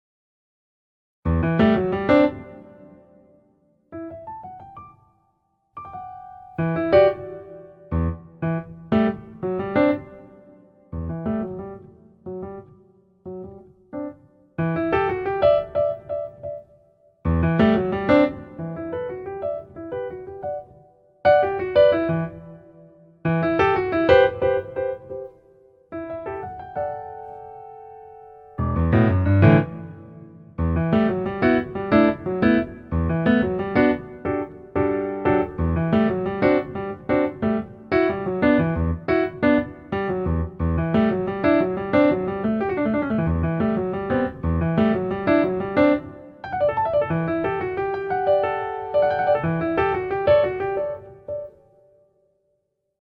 A piano AI (artificial intelligence)